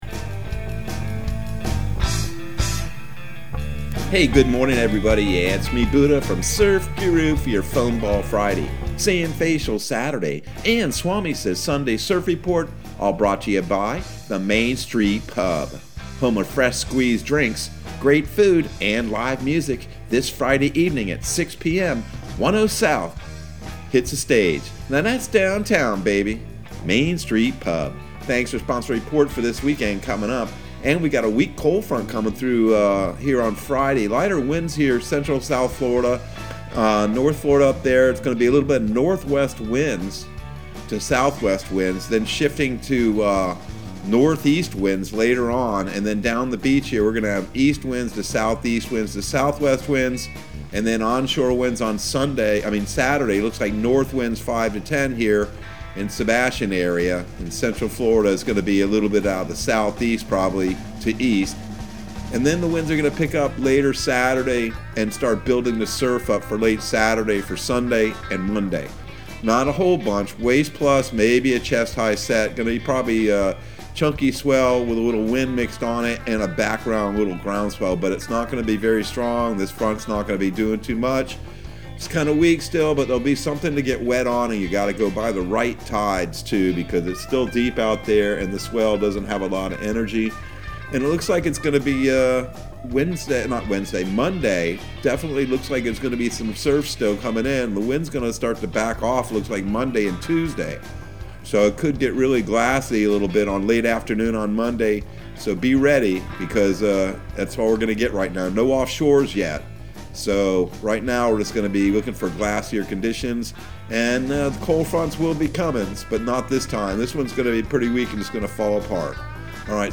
Surf Guru Surf Report and Forecast 10/22/2021 Audio surf report and surf forecast on October 22 for Central Florida and the Southeast.